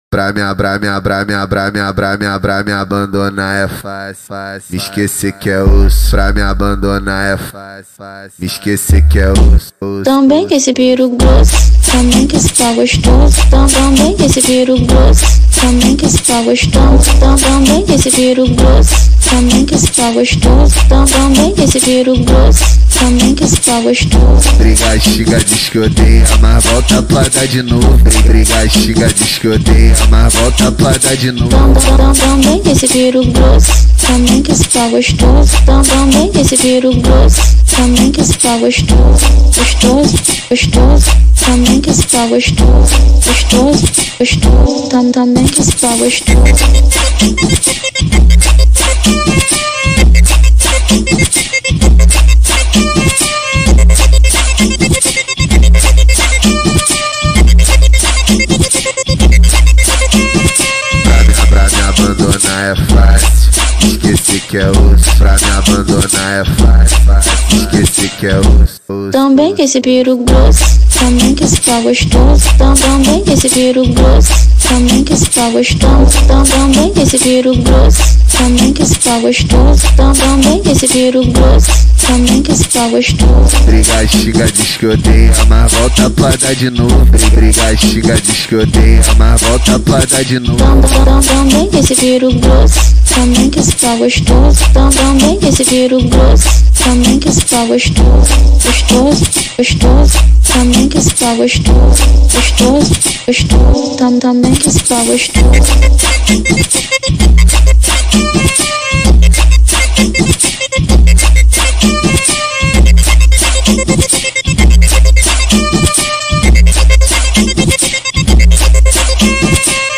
2025-01-31 22:34:47 Gênero: Funk Views